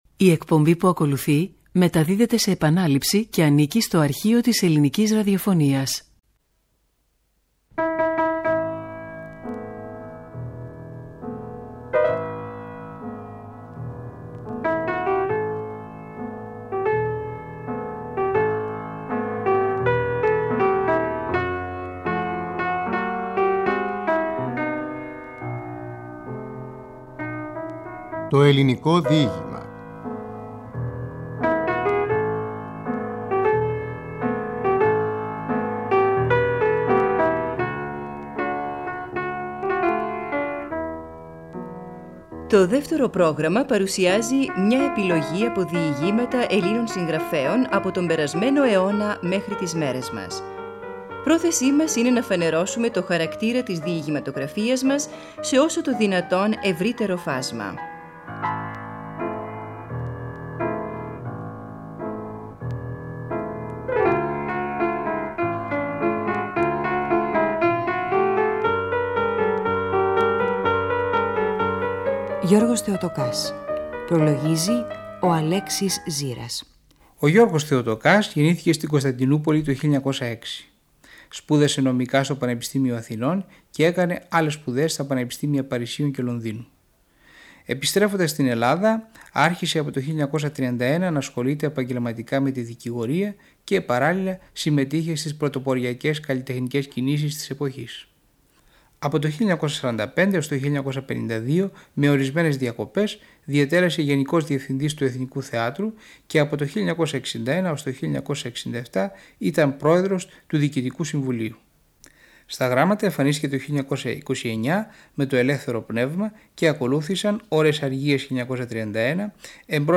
Από τη σειρά εκπομπών με τίτλο «Το Ελληνικό Διήγημα» στο Δεύτερο Πρόγραμμα.
Ο Στέφανος Ληναίος διαβάζει τα διηγήματα «Παλαιό Κάιρο» και «Αρχαία Αίγυπτος» .